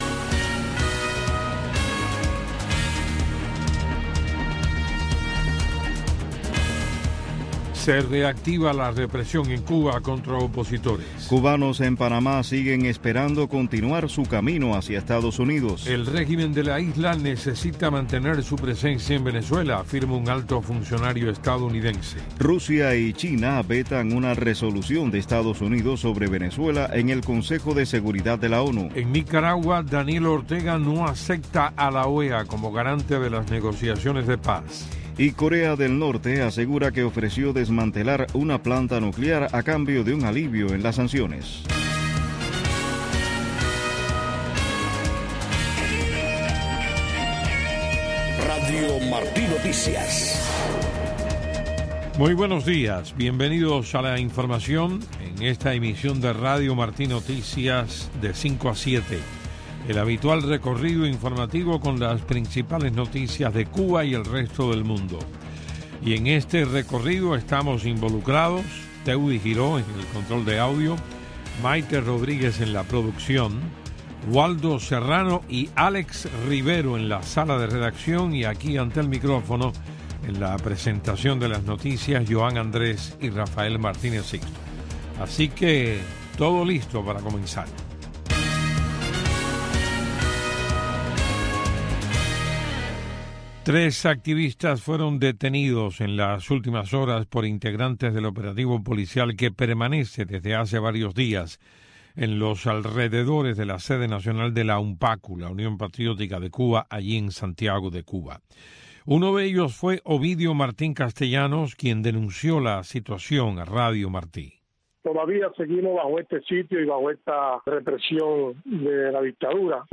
Noticiero de Radio Martí 6:00 AM